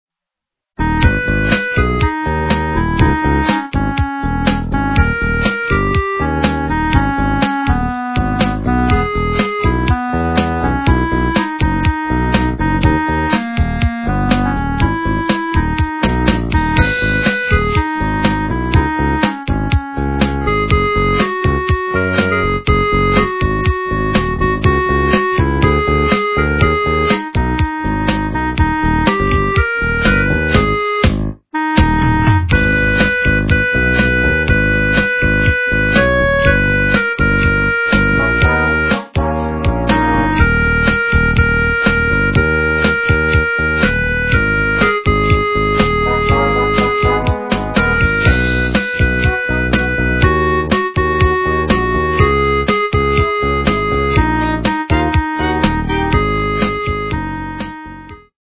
русская эстрада
качество понижено и присутствуют гудки.
полифоническую мелодию